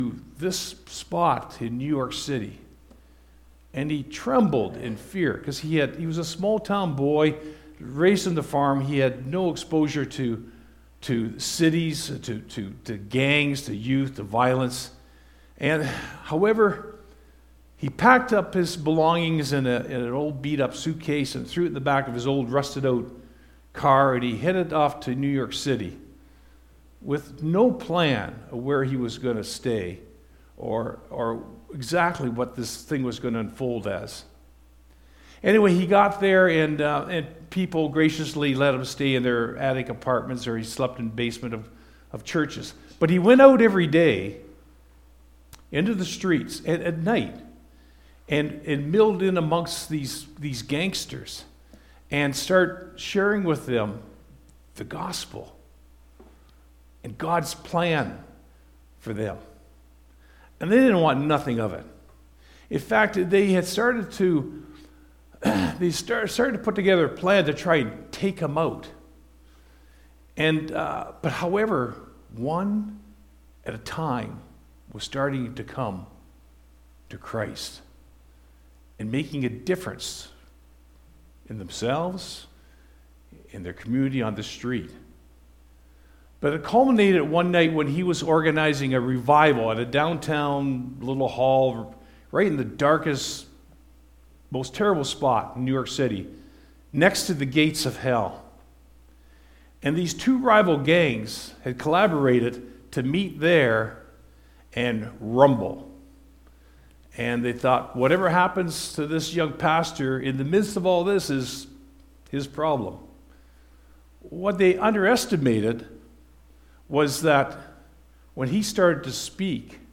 Sermon Audio and Video I'm Gonna Let it Shine!